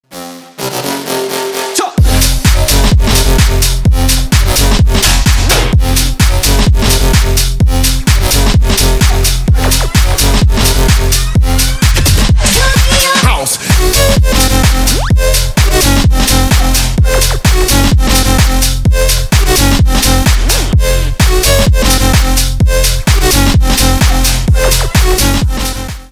Громкие Рингтоны С Басами
Рингтоны Электроника